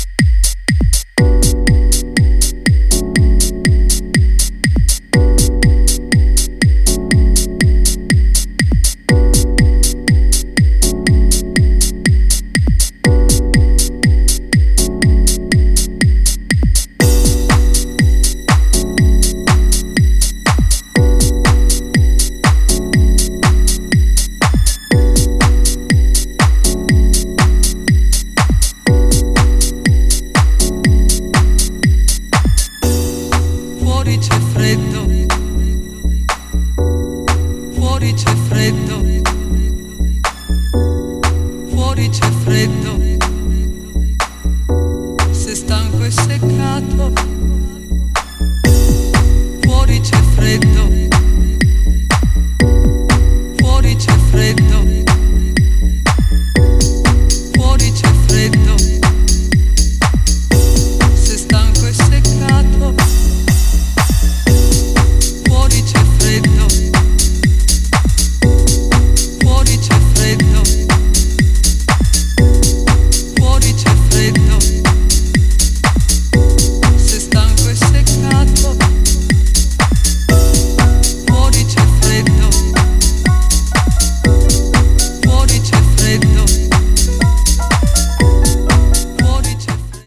Piano Mix